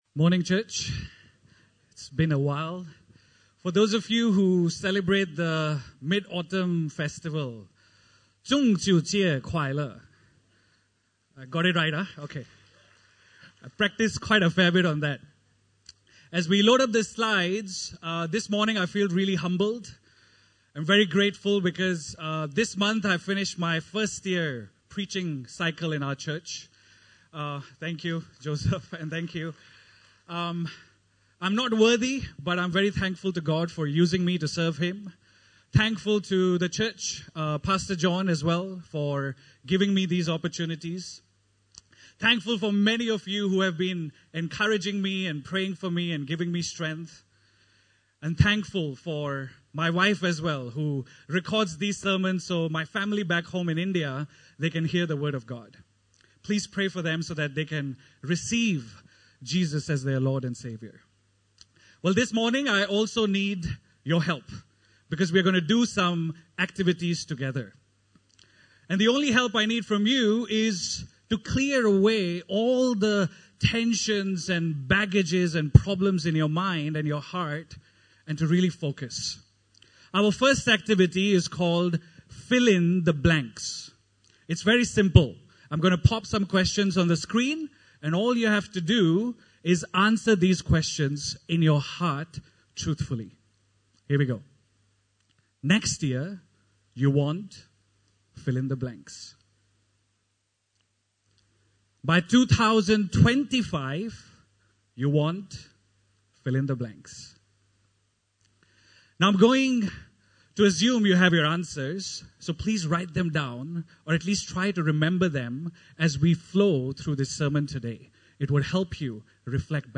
Service Type: Sunday Service (Desa ParkCity)